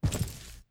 Footstep_Concrete 05.wav